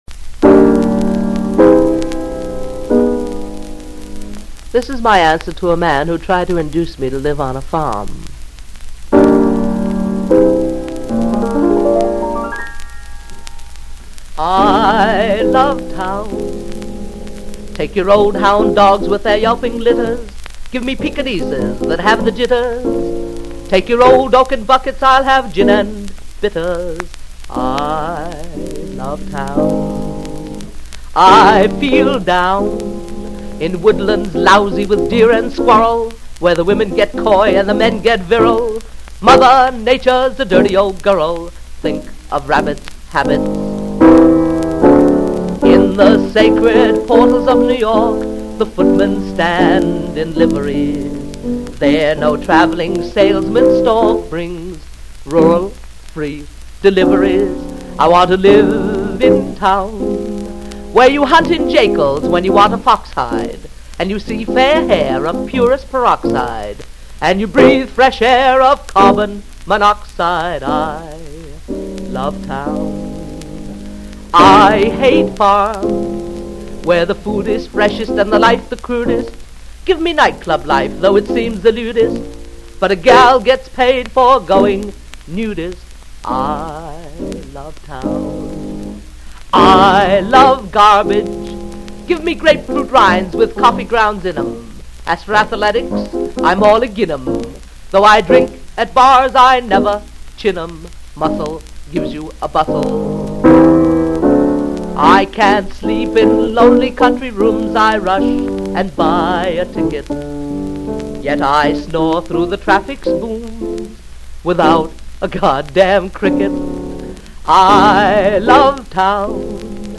--w. piano, New York City, Oct., 1939 Reeves Sound Studios.